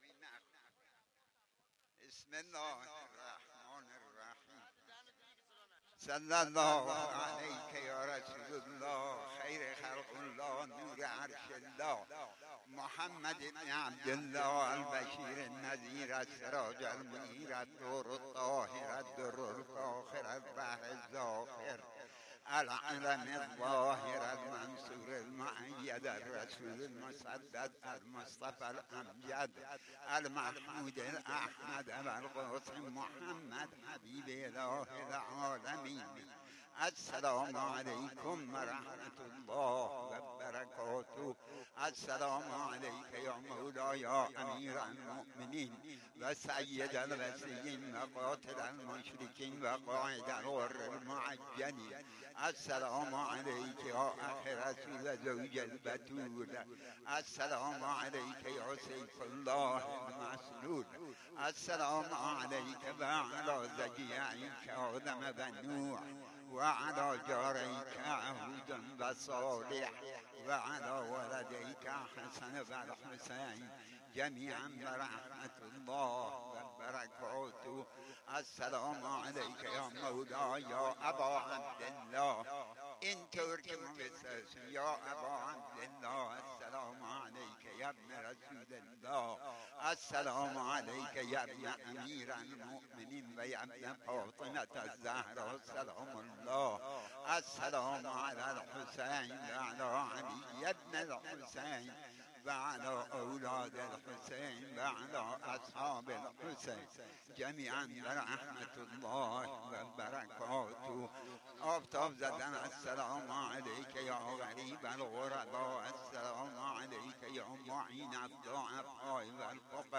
صدایی که در ادامه می توانید دریافت کنید و گوش دهید مربوط است به آخرین زیارتی که ایشان در محرم سال ۱۳۹۰ در شاه محمد دیده بان خوانده اند: